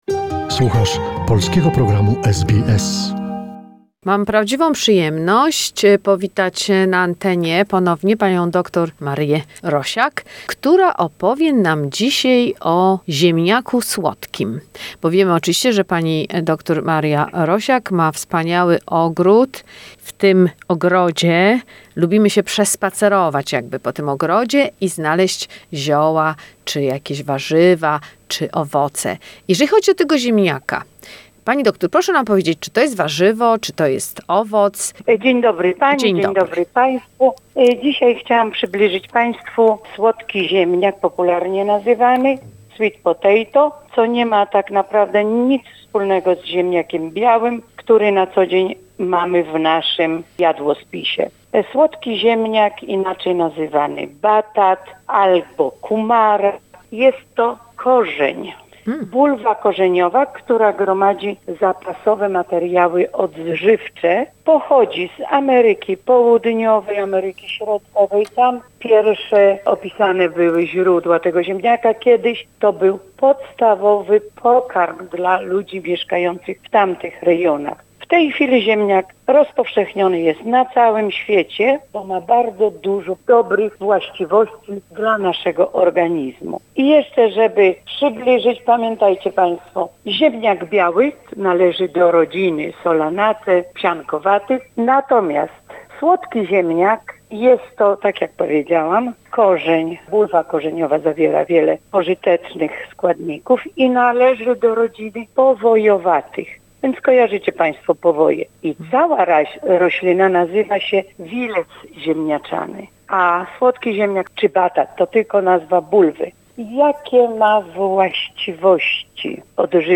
The conversation